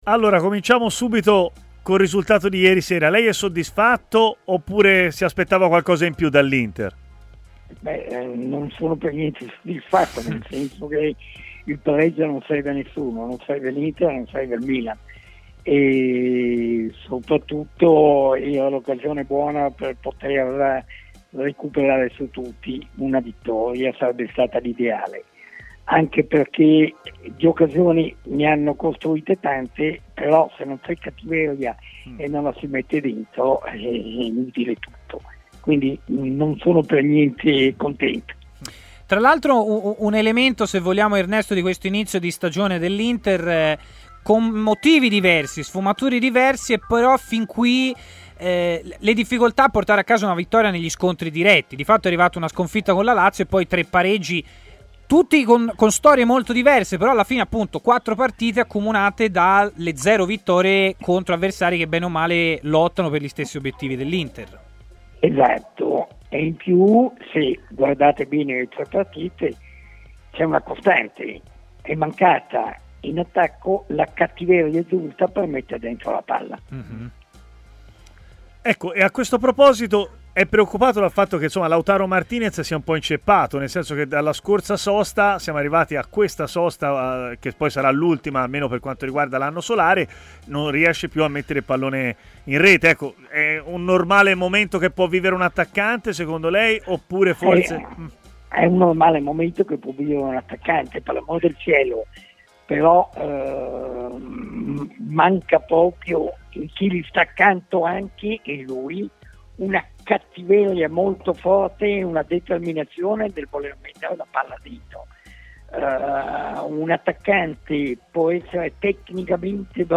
trasmissione in onda sulle frequenze di TMW Radio